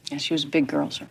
Jodie Foster in Silence of the Lambs